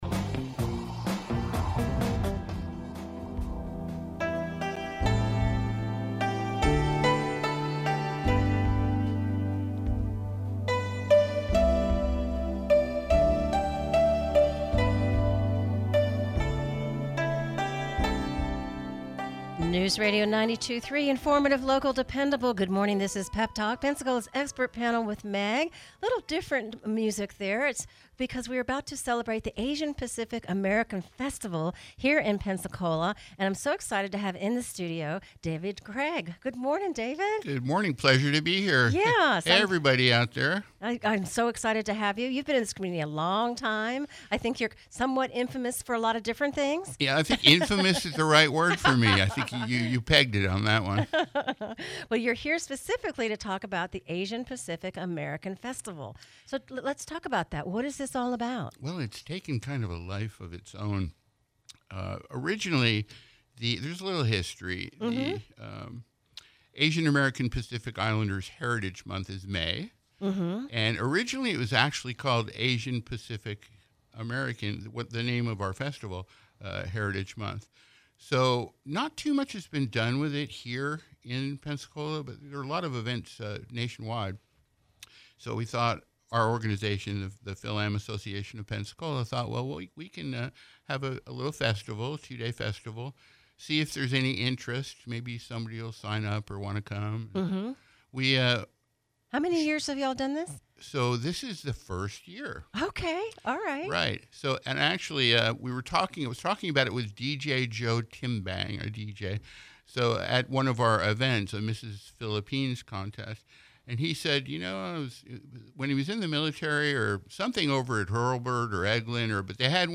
Encore Broadcast